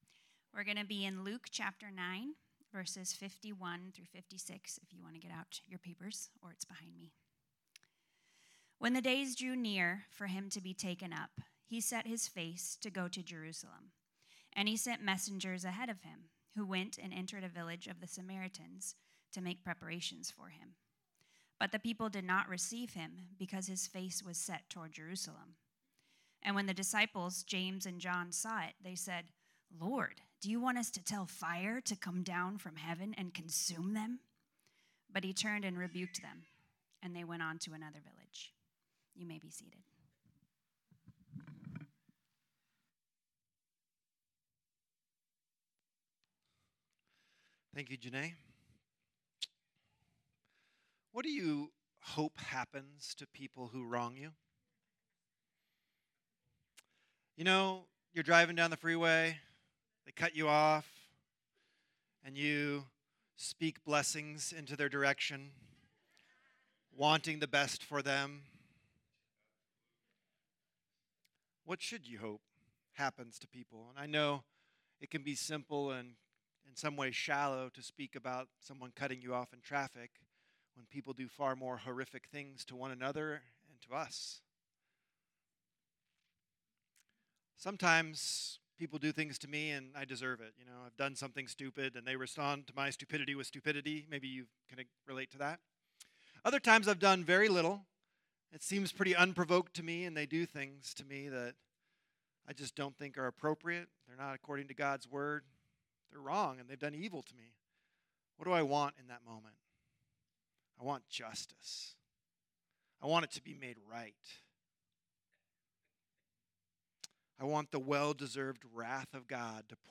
Type: Sermons , Sermon